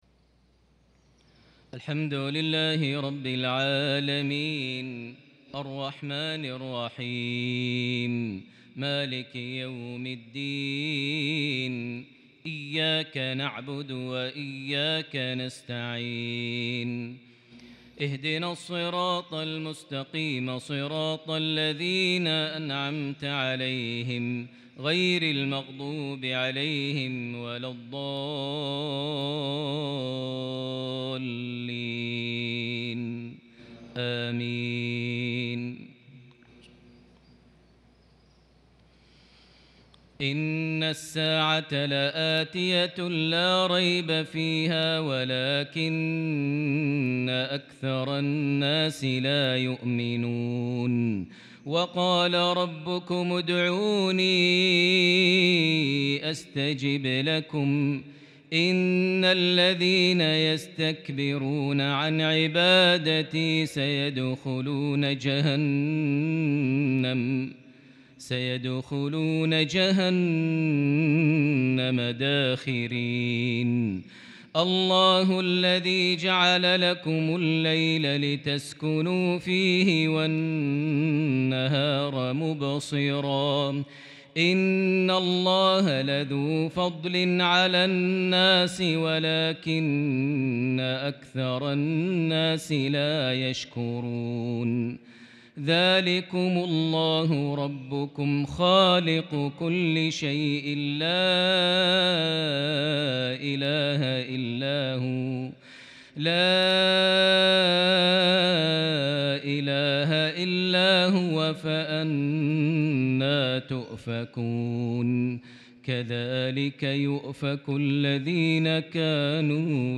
mghrip 9-9- 2021 prayer from Surah Ghafir 59-65 > 1443 H > Prayers - Maher Almuaiqly Recitations